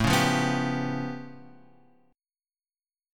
A Augmented 9th
A+9 chord {5 4 3 4 x 3} chord